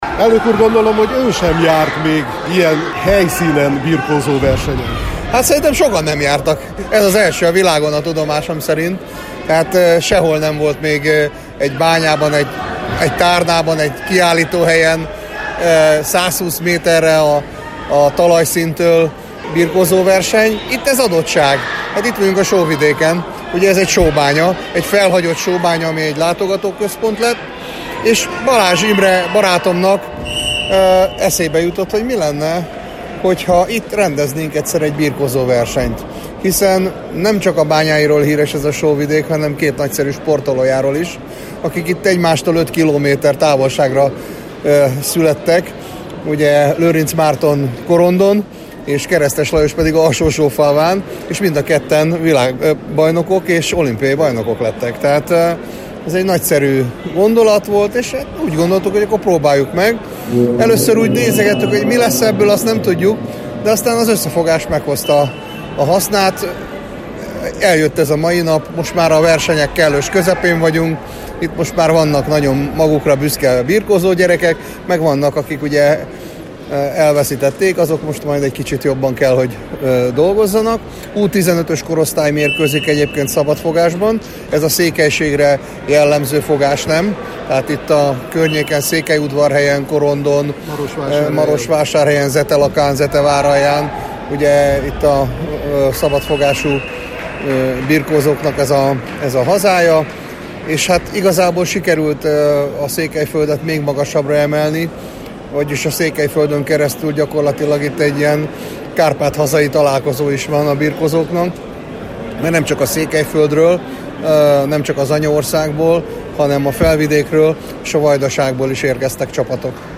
Az eseményt megtisztelte Németh Szilárd a Magyar Birkózó Szövetség elnöke is, szerinte a verseny mellett nagyon fontos, hogy a magyar lakta területek a sporton keresztül is tartsanak össze: